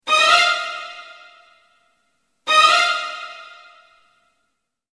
Hiệu ứng âm thanh Ấn Tượng